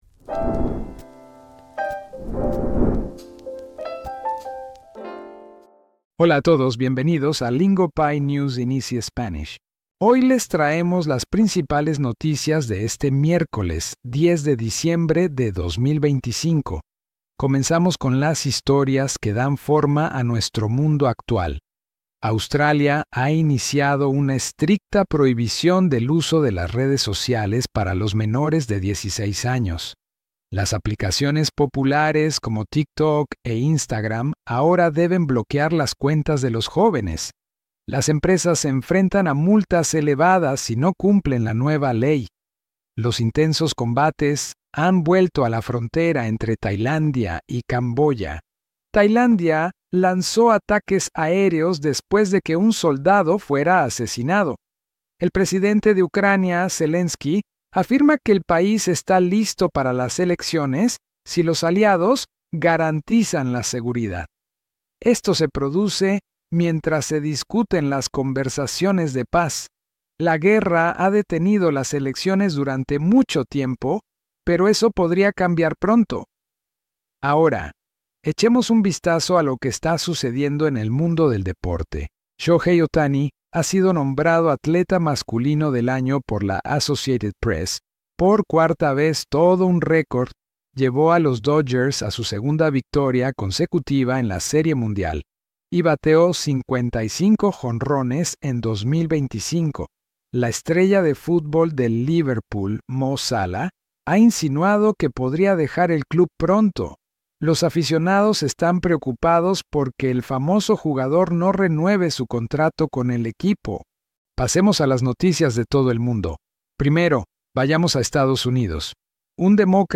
No grammar drills, no textbook exercises, just real news told in Spanish you can actually follow.